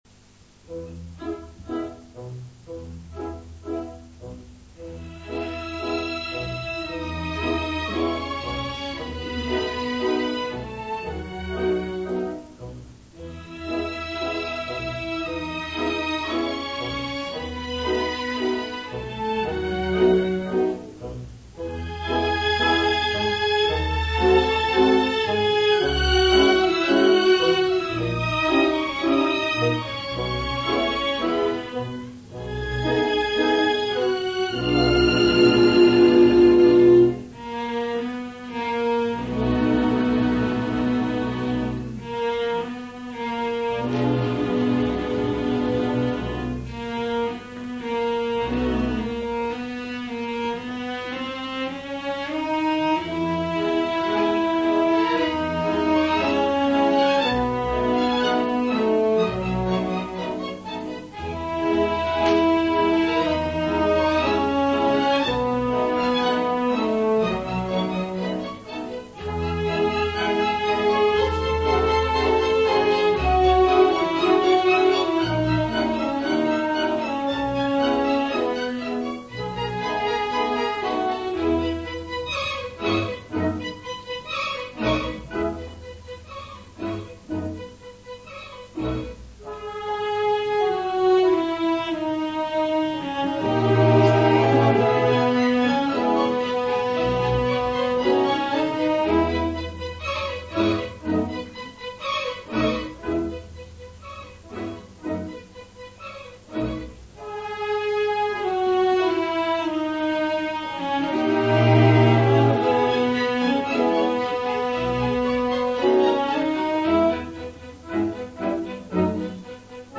Piccolo archivio multimediale delle passate stagioni artistiche del teatro Verdi di Buscoldo.